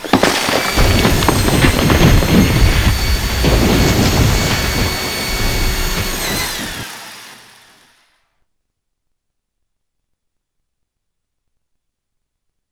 drill.wav